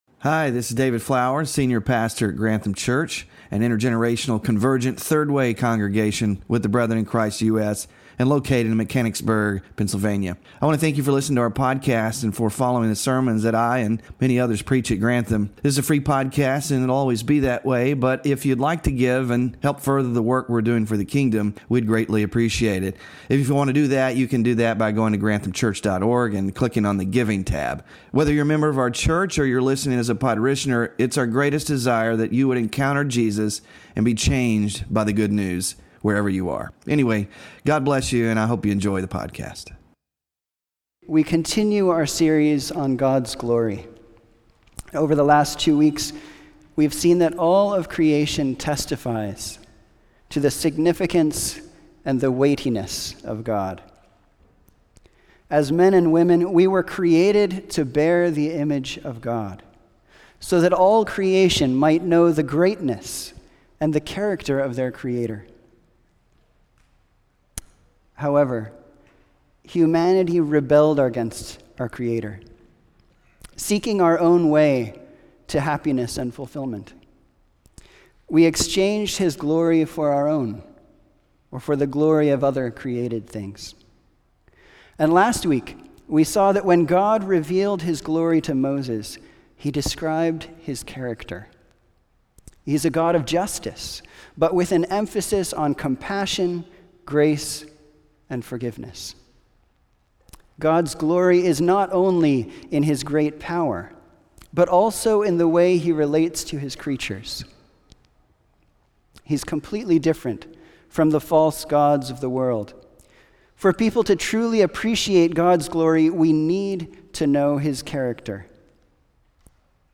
GLORY OF GOD WK3 SERMON SLIDES SMALL GROUP DISCUSSION QUESTIONS (5-19-24)